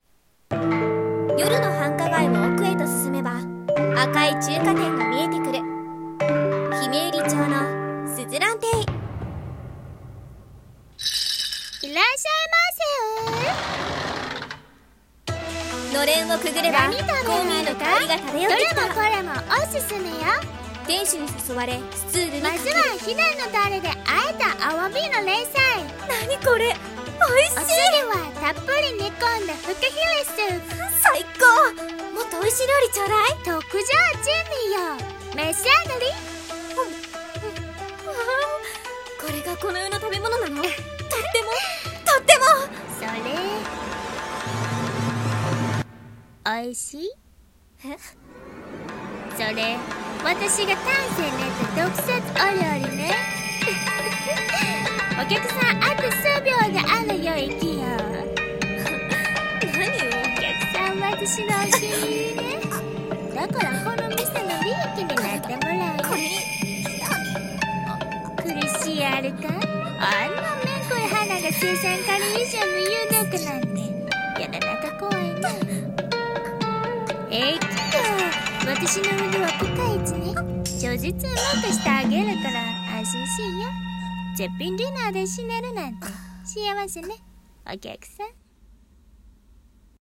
【声劇】珍味の鈴蘭中華店